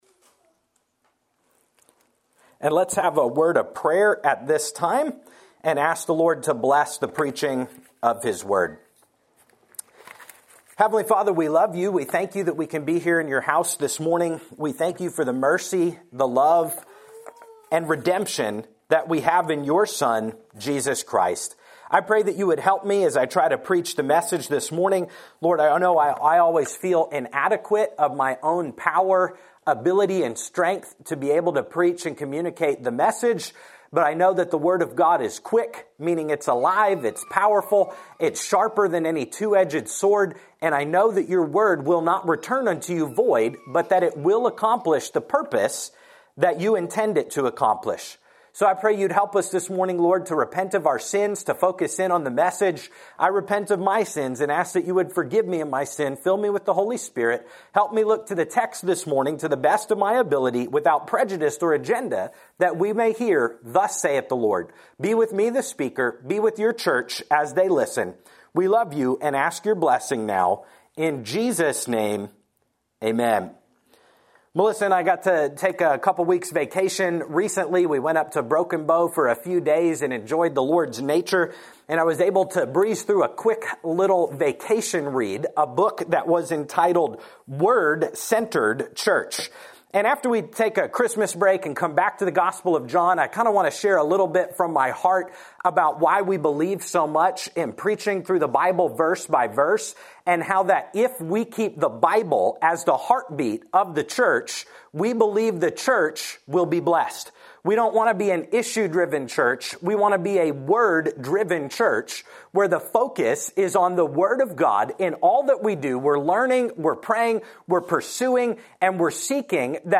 Sermons | Renewal Baptist Church